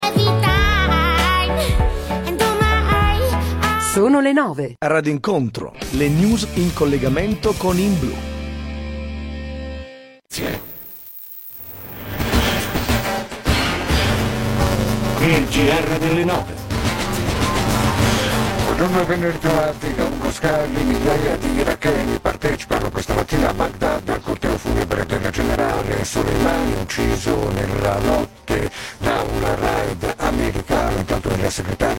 Audio regolarmente in onda fino alla partenza dell'EXT1 dove l'audio diventa distorto ed inascoltabile.
Ho inviato il debug e vi allego i files delle registrazioni di inizio e fine distorsione per rendervi conto.
inizio distorsione